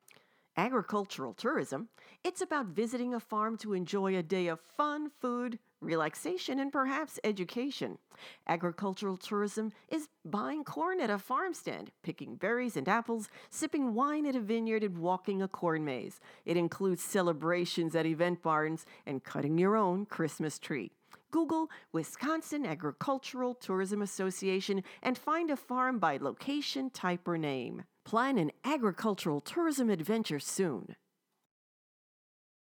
Wisconsin Agricultural Tourism – Public Service Announcements (PSAs)